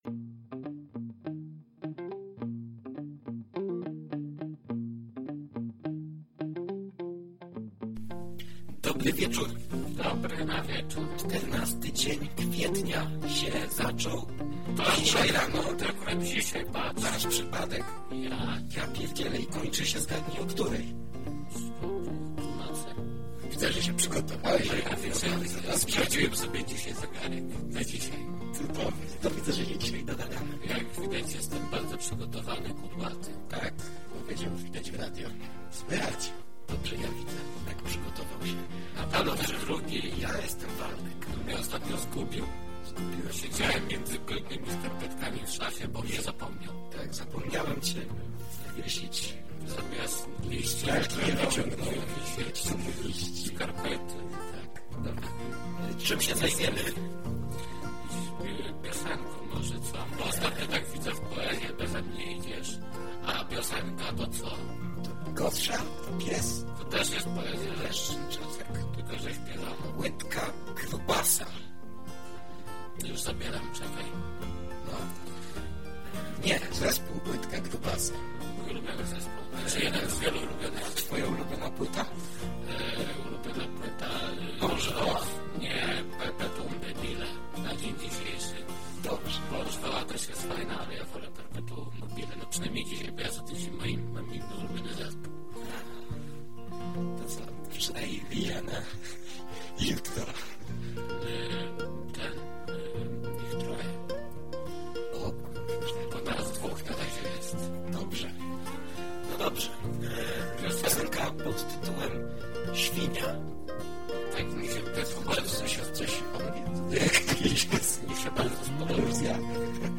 "Co Ałtor Miał na Myśli" to audycja rozrywkowa, nagrywana co tydzień lub dwa.